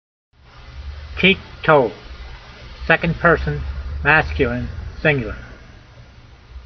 My reading (voice) in modern Israeli style is only good enough to get you started.
teek-tol